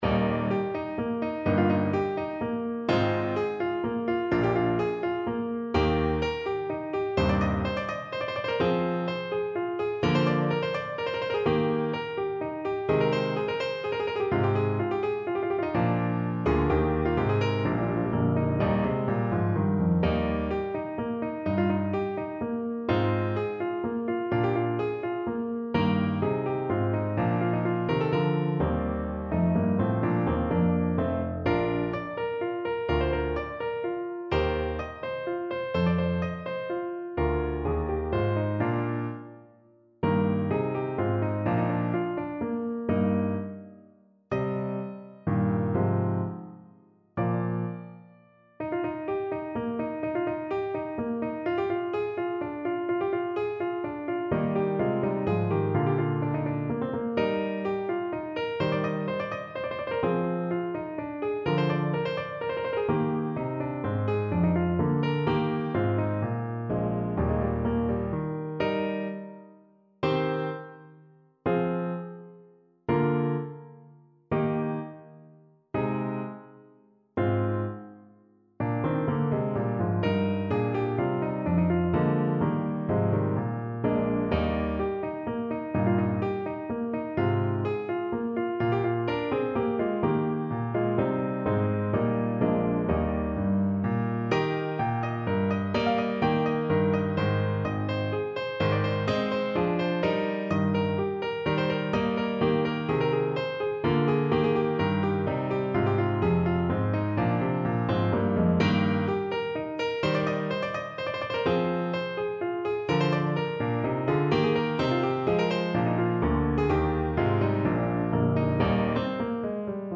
Free Sheet music for Alto Saxophone
Eb major (Sounding Pitch) C major (Alto Saxophone in Eb) (View more Eb major Music for Saxophone )
= 126 Allegro (View more music marked Allegro)
3/4 (View more 3/4 Music)
Classical (View more Classical Saxophone Music)